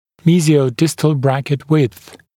[ˌmiːzɪəu’dɪstl ‘brækɪt wɪdθ][ˌми:зиоу’дистл ‘брэкит уидс]мезиодистальная ширина брекета